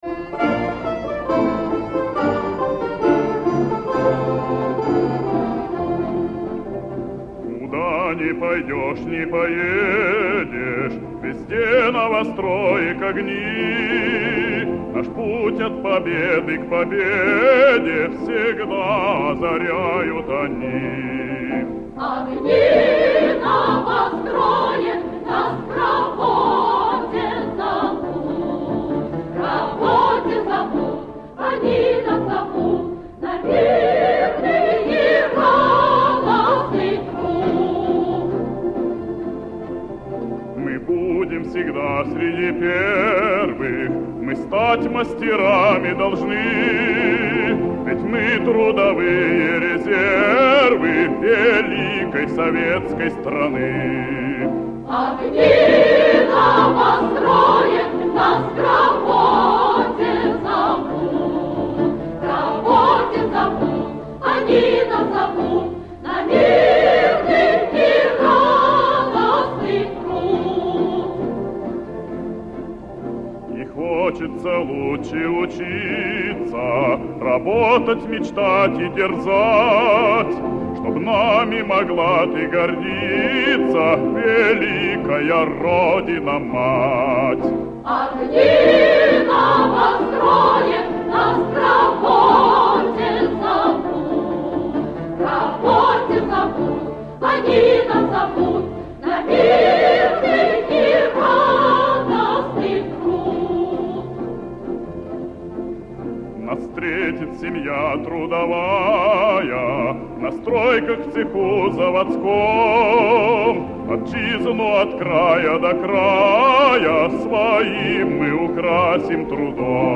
хор